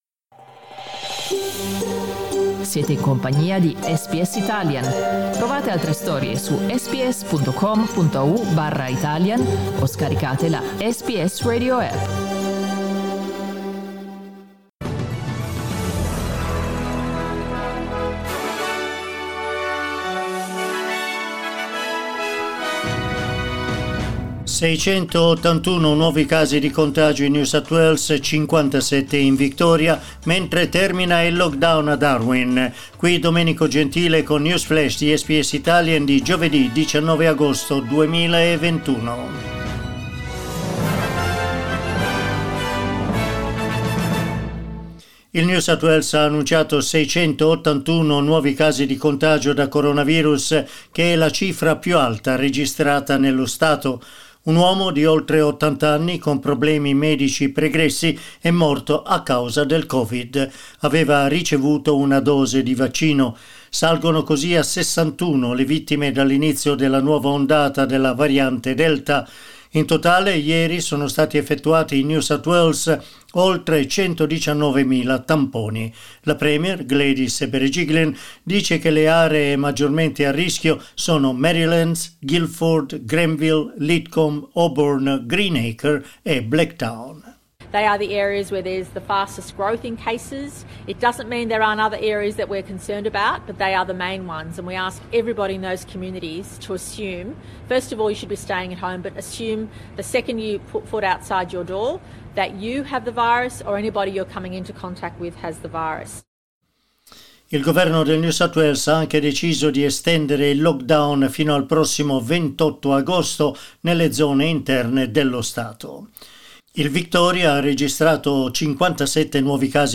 News Flash giovedì 19 agosto 2021